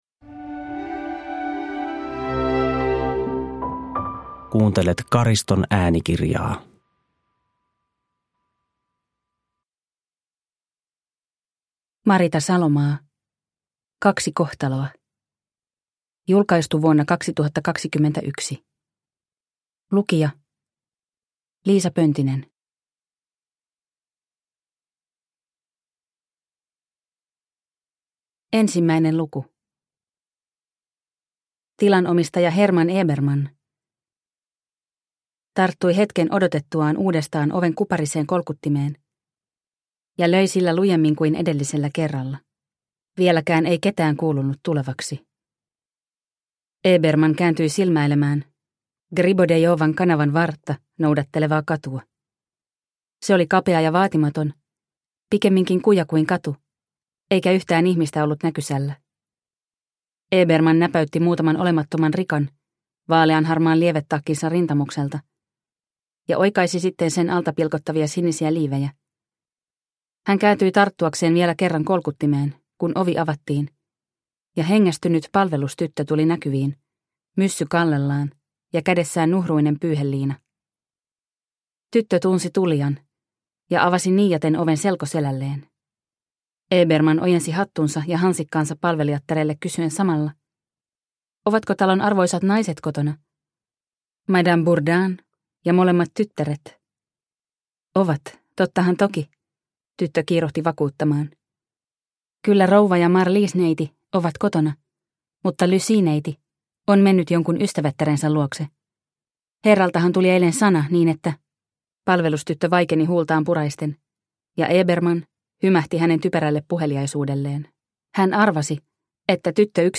Kaksi kohtaloa – Ljudbok – Laddas ner